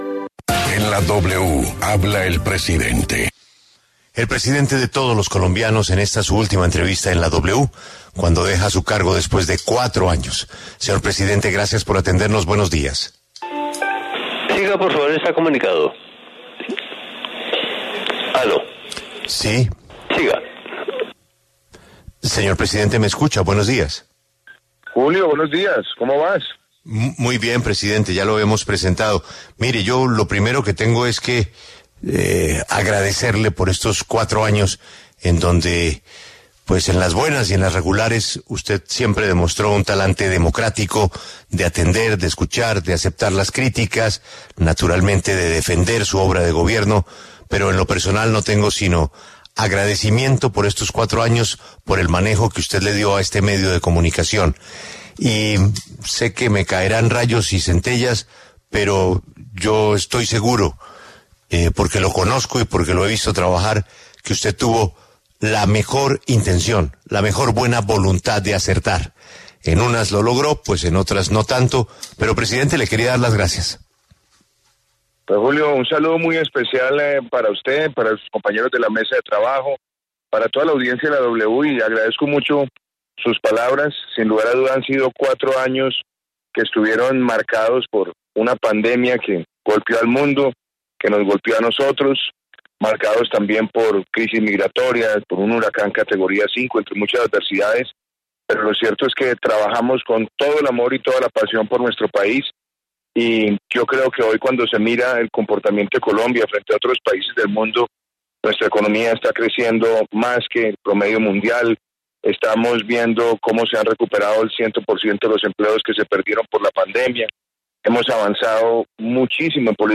En diálogo con La W, el saliente presidente Iván Duque hace un balance sobre su gestión como presidente de la República.